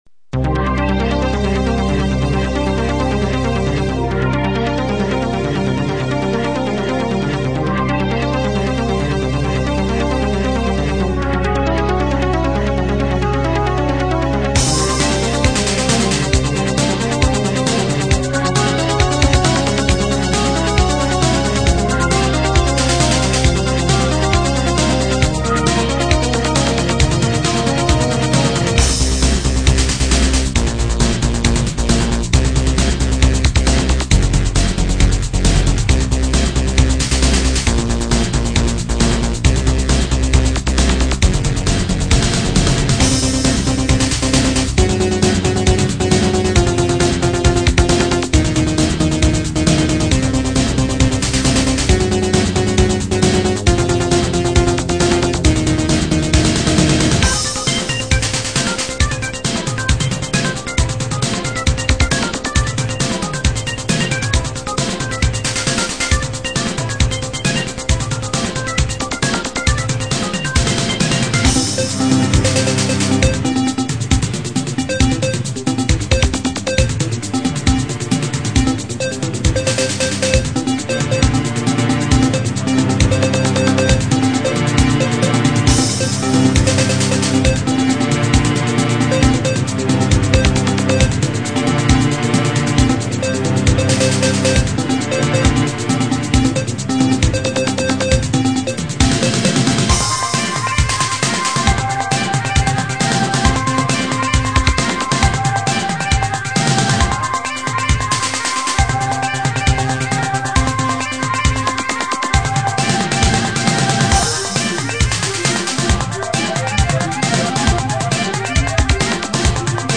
recorded midi